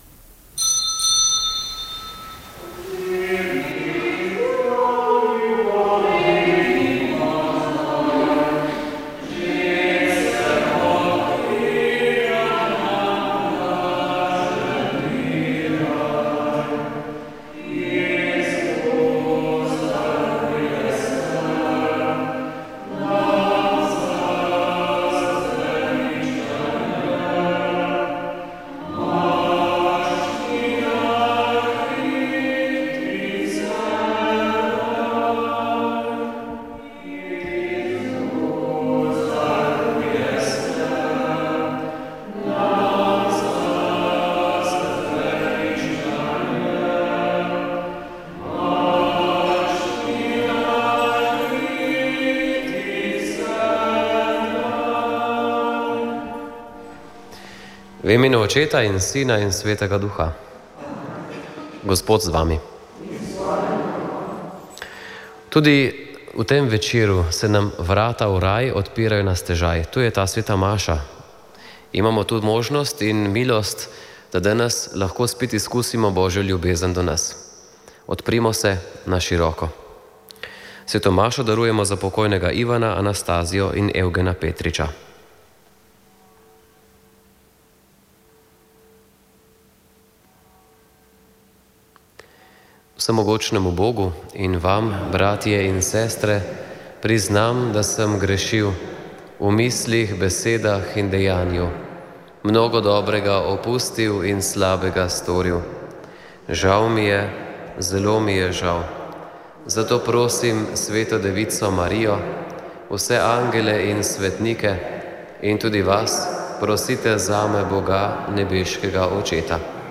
Sveta maša
Sv. maša iz župnijske cerkve Svete Elizabete Ogrske Ljubno ob Savinji 26. 5.